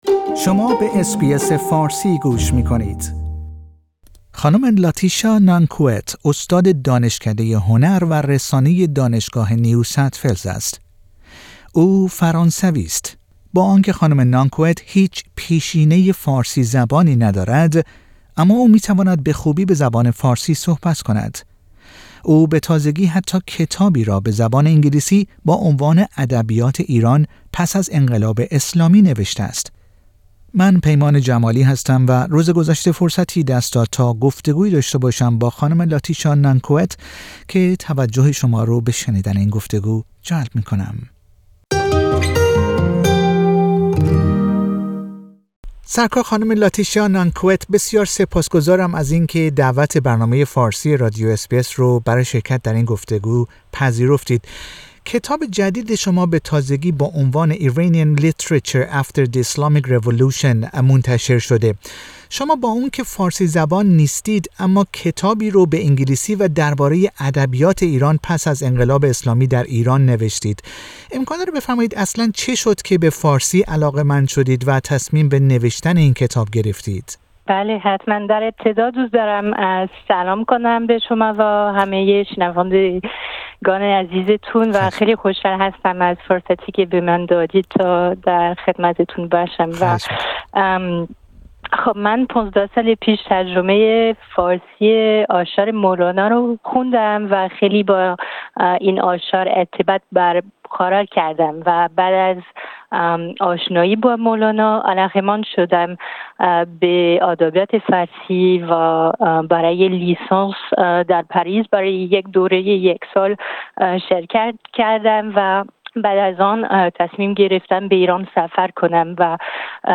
در گفتگو با برنامه فارسی رادیو اس بی اس درباره آشنایی خود با زبان فارسی و دلیل نگارش این کتاب سخن می گوید.